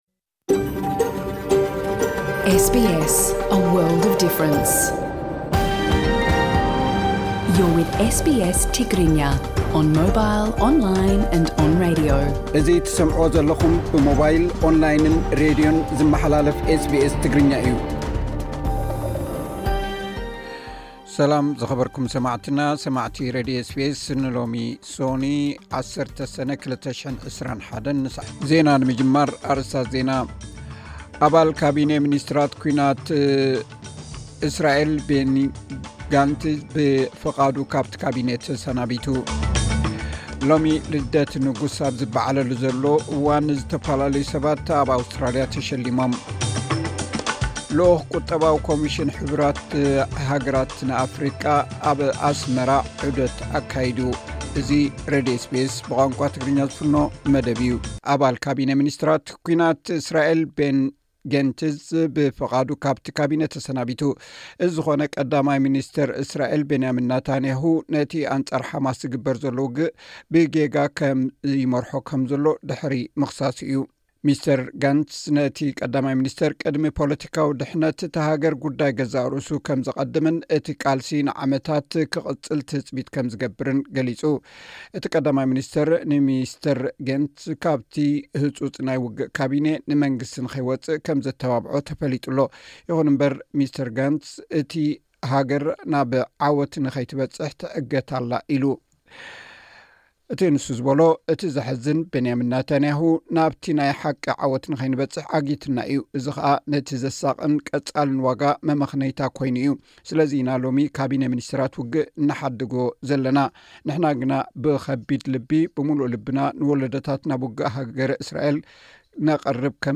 ዕለታዊ ዜና ኤስ ቢ ኤስ ትግርኛ (10 ሰነ 2024)